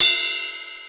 Crash 3.wav